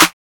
TM-88 Clap #15.wav